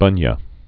(bŭnyə)